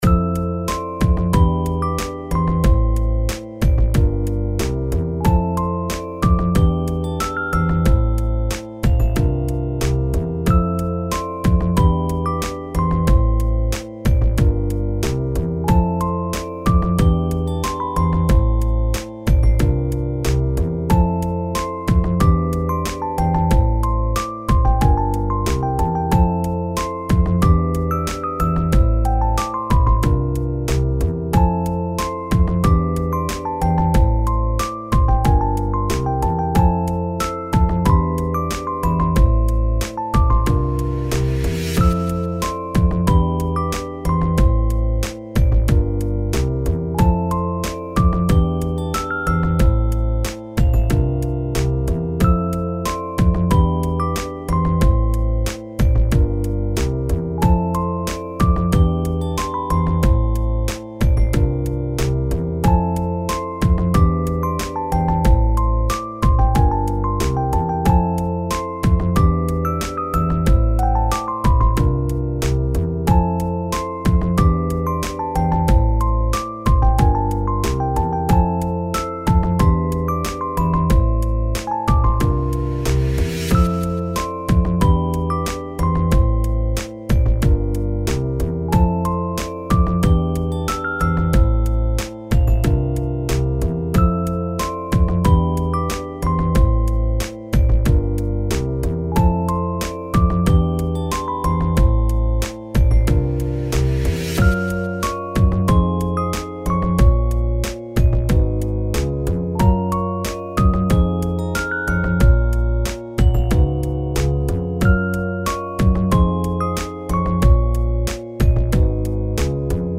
Chill